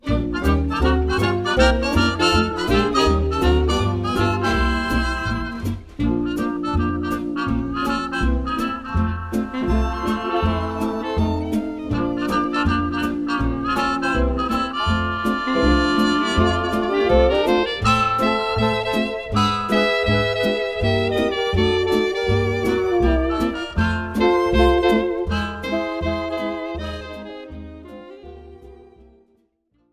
instrumental backing track cover
• Without Backing Vocals
• No Fade